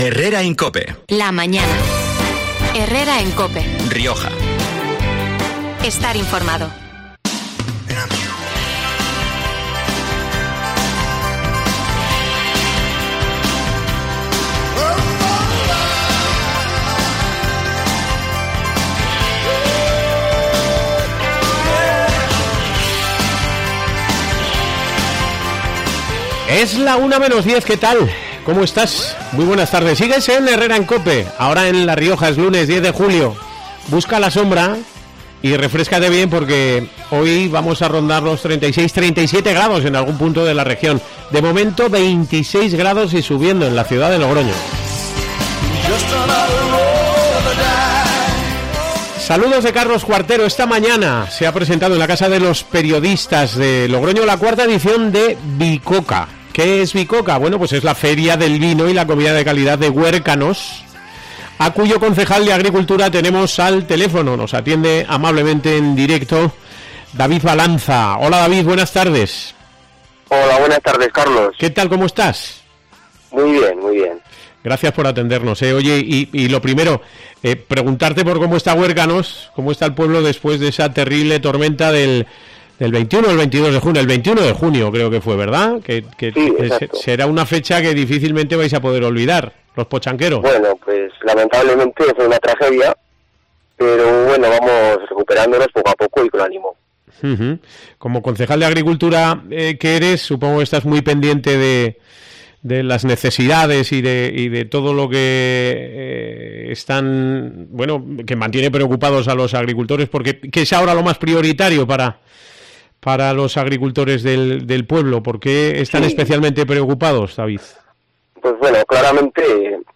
David Balanza, concejal de Agricultura de Huércanos, ha explicado en COPE Rioja que en la IV edición de VICOCA, que comenzó en 2018 pero se paró por dos años debido a la pandemia, se volverá a celebrar la única feria de vinos de cooperativa de La Rioja, cuyo objetivo principal es reivindicar la figura de las cooperativas agroalimentarias en los municipios riojanos y su aportación a la generación de riqueza y empleo en el medio rural.